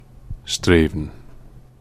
Strathaven (/ˈstrvən/
STRAY-ven; from Scottish Gaelic: Strath Aibhne [s̪t̪ɾah ˈajnə]) is a historic market town in South Lanarkshire, Scotland and is the largest settlement in Avondale.